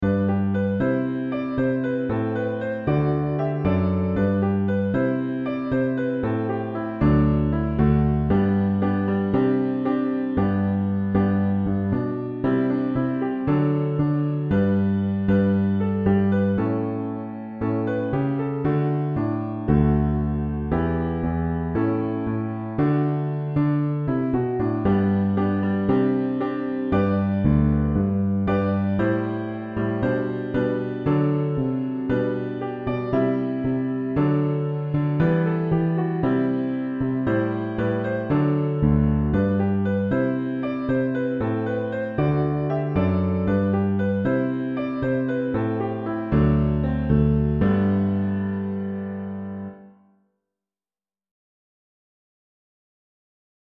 That being understood, here is a computer-generated recording of
a liturgical ensemble arrangement of SINE NOMINE (keyboard and bass guitar).
The arrangement includes an intro/outro/bridge, all of which are largely identical.